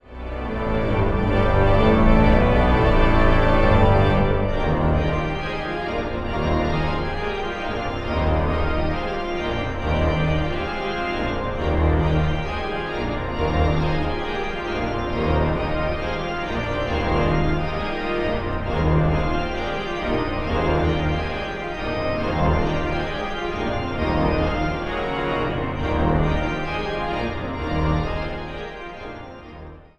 Locatie: Amsterdam | Concertgebouw